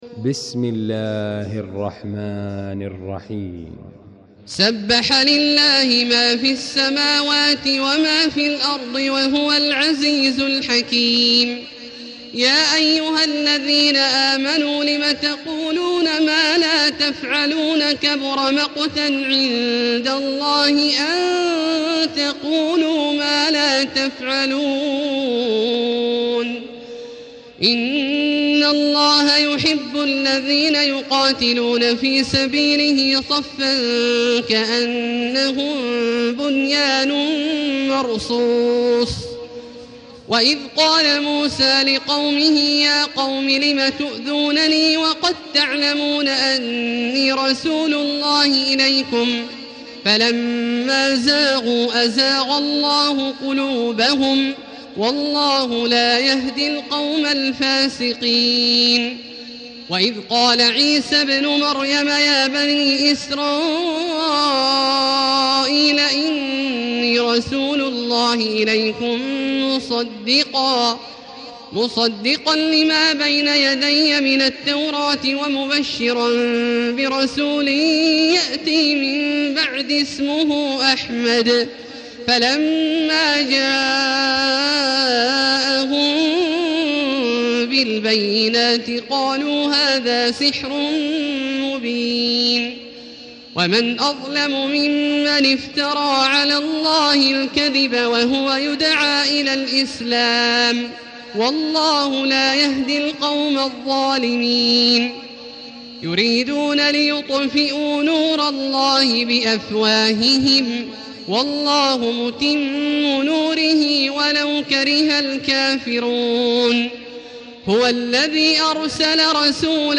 المكان: المسجد الحرام الشيخ: فضيلة الشيخ عبدالله الجهني فضيلة الشيخ عبدالله الجهني الصف